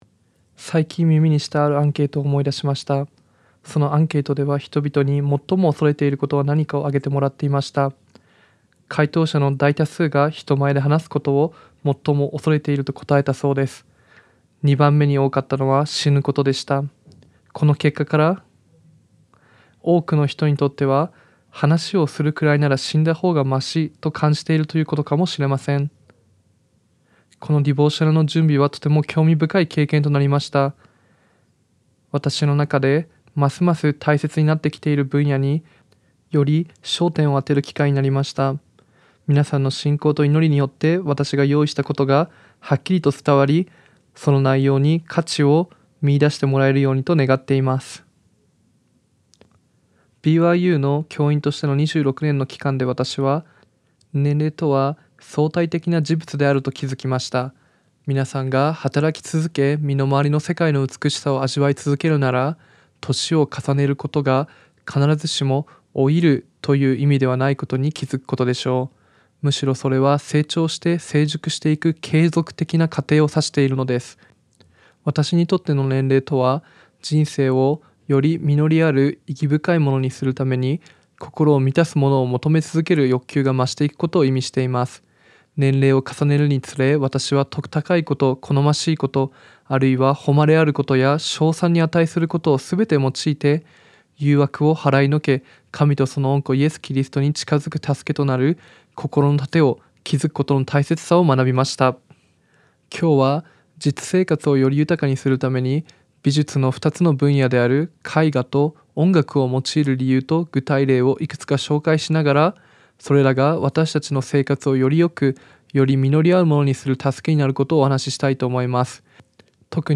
ディボーショナル